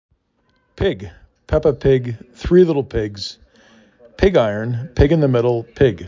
pig